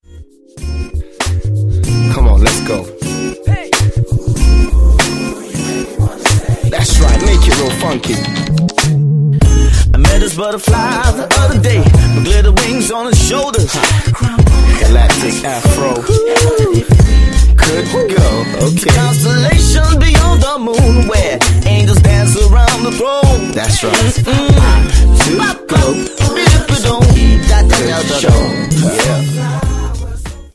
Grandiose Vokalarrangements, vibrierende
Beats und eine klare Botschaft.
• Sachgebiet: Gospel